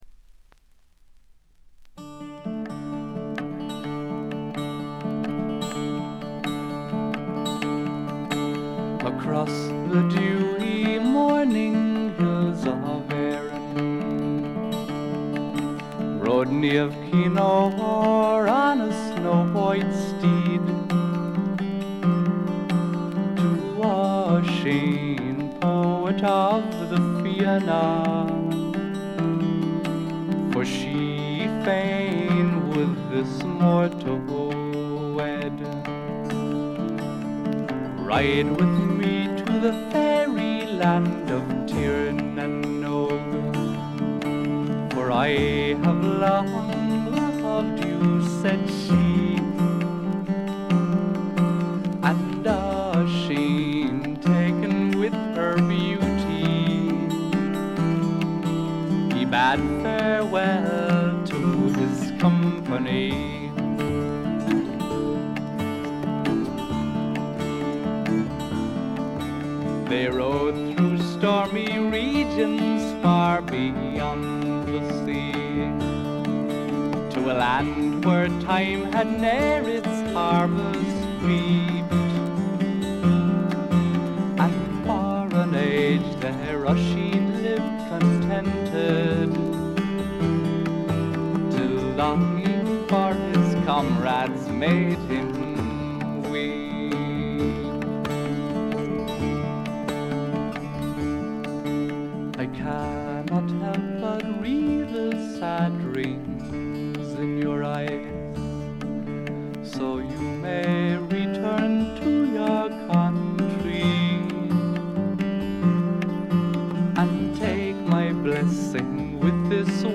基調は霧の英国フォークでありながらも、幻想的で、ドリーミーで、浮遊感たっぷりで、アシッドな香りも・・・。
試聴曲は現品からの取り込み音源です。